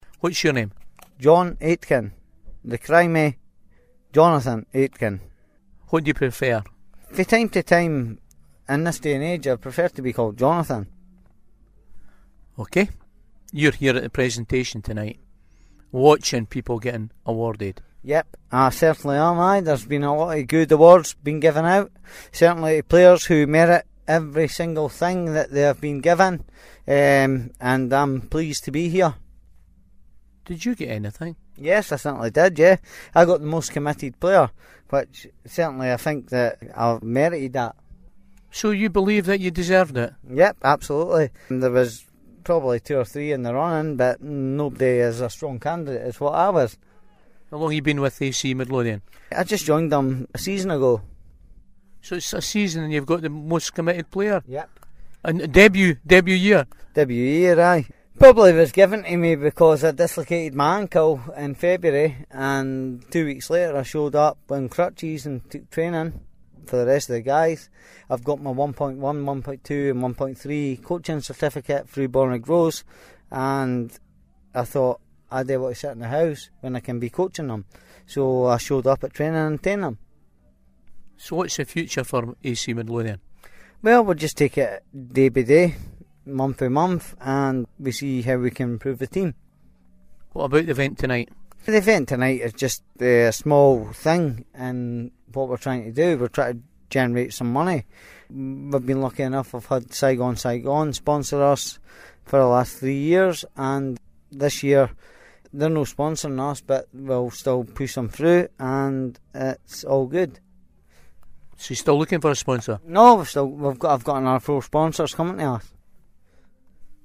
AC Midlothian Presentation Evening